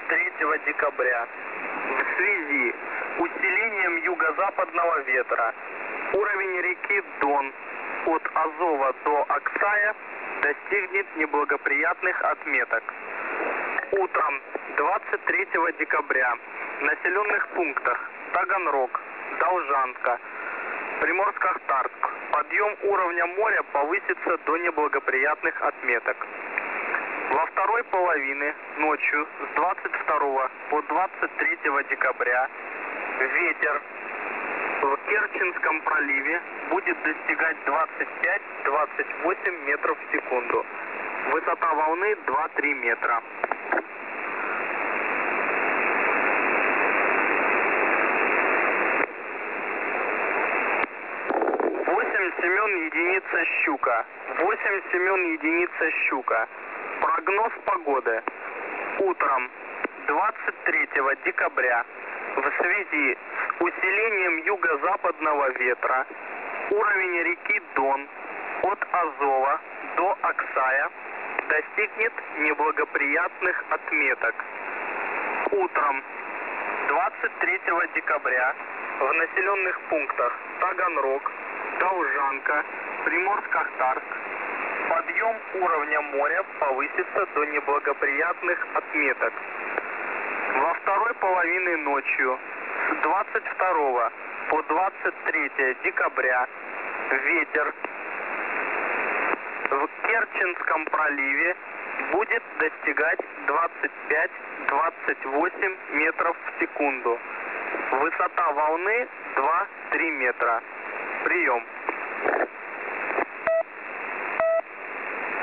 Time (UTC): 14:22 Mode: USB Frequency: 3756 Message: Unecrypted weather forecast for 23 December high water level warning in river Don because of high winds. Also the sea level is on the rise.